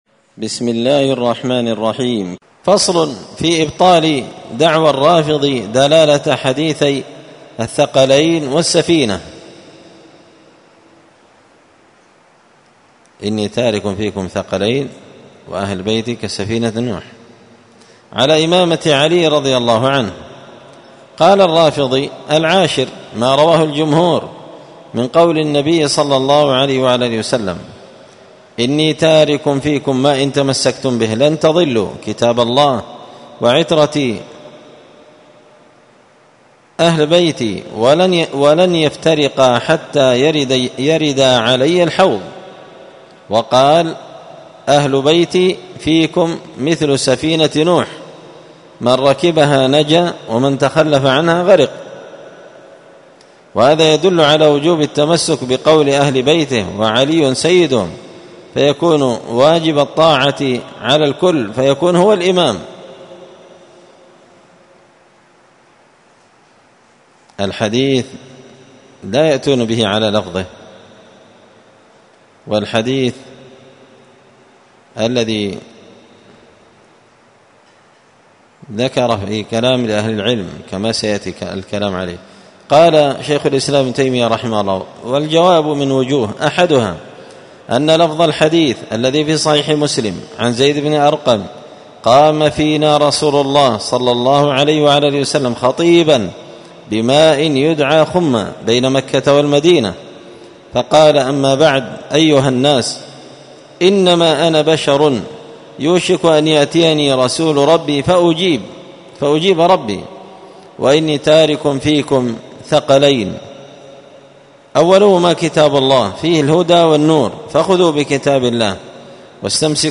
الدرس المائتين (200) فصل في إبطال دعوى الرافضي دلالة حديثي الثقلين والسفينة على إمامة علي